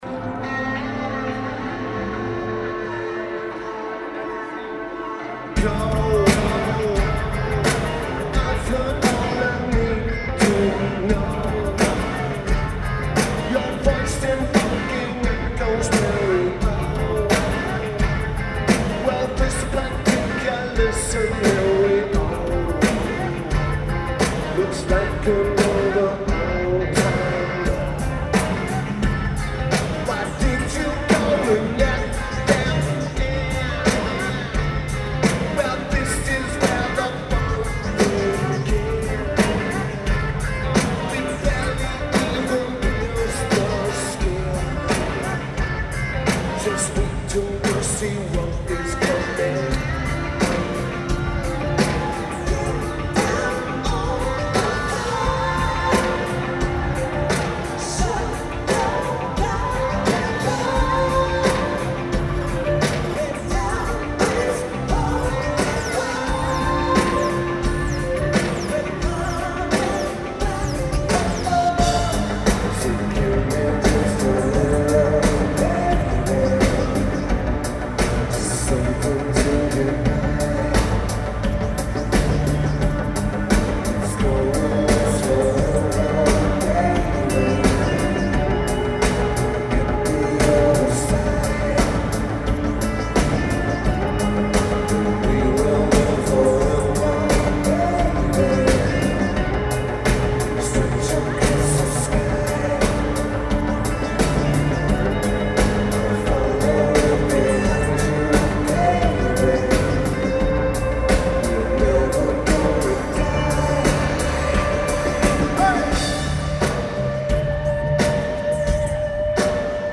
Wolstein Center
Lineage: Audio - AUD (DPA 4060 + DPA MPS6030 + Sony PCM-M10)
Notes: Average recording.